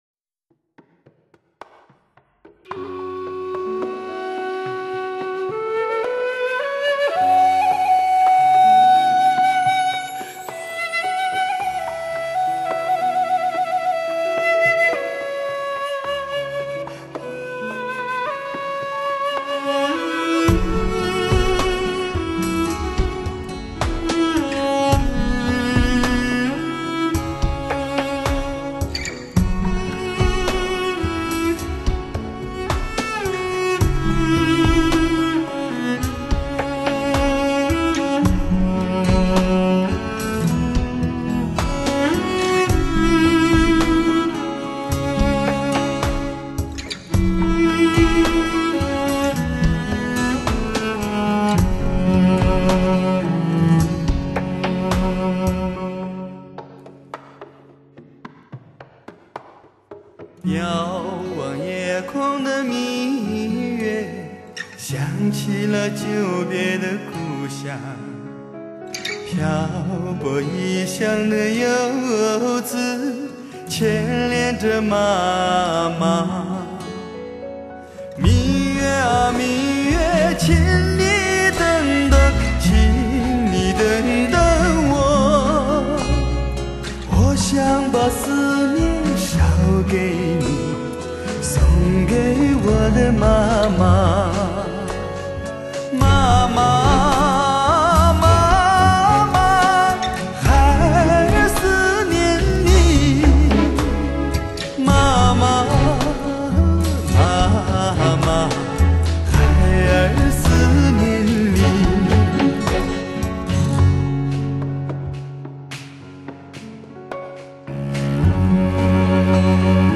粗砺壮阔的西域 独有的史诗吟唱
高亢恬远的大漠气概，神奇般的将眼前的奢华消弭，心胸开阔而空灵。
那，时而低频婉转、时而雄厚深邃，时而豪放流畅的吟唱，